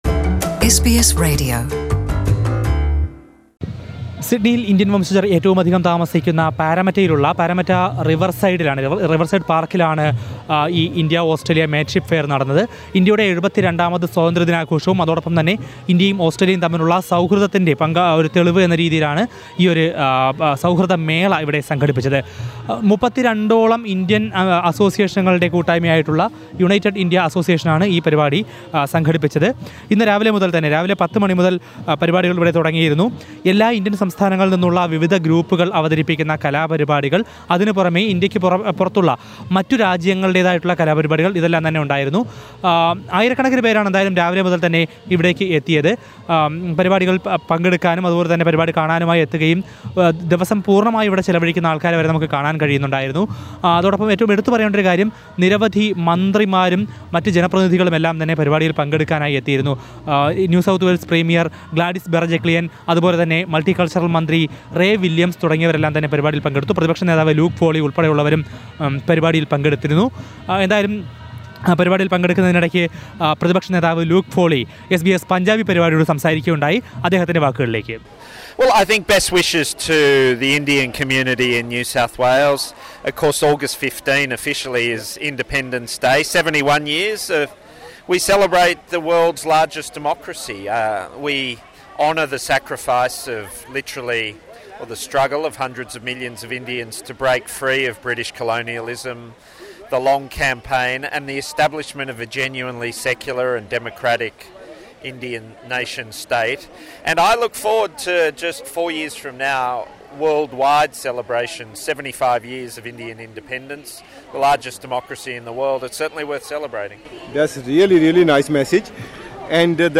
Listen to a report from the India-Australia Mateship Fair organised by United Indian Associations in Sydney.